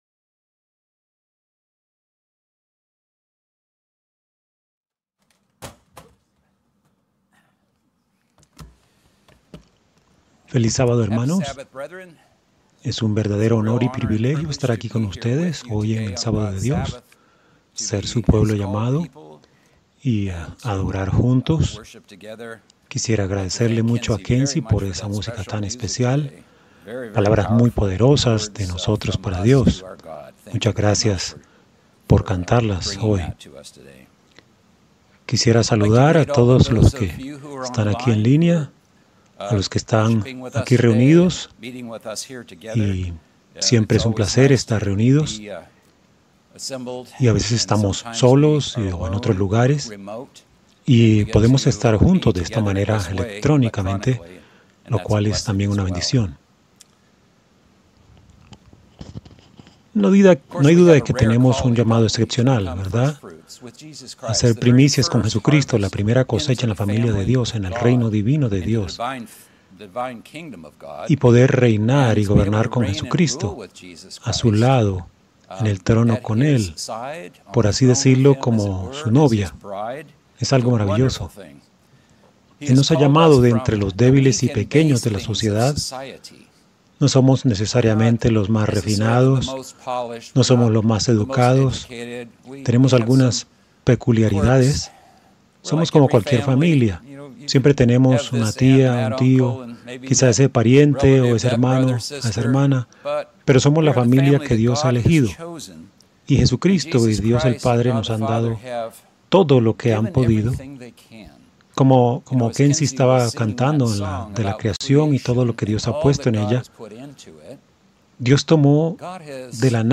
Sermones